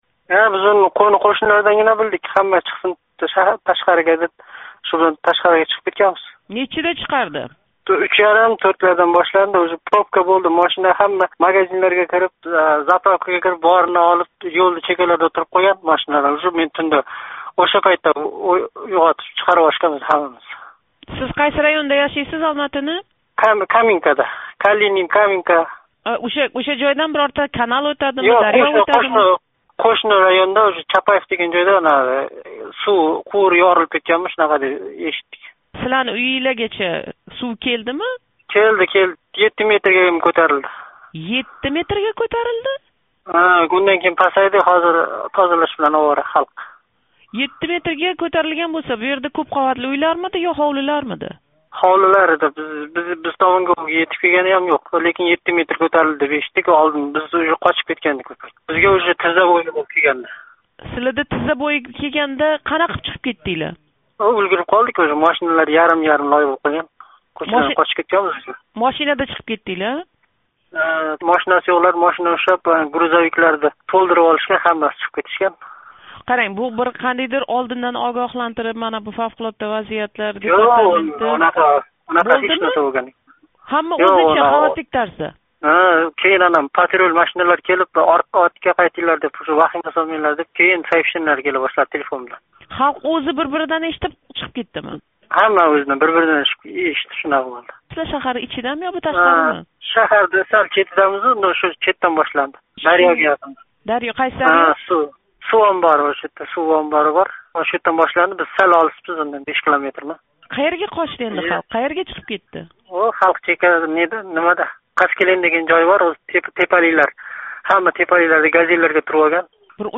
Олмаотадаги ўзбек муҳожири билан суҳбат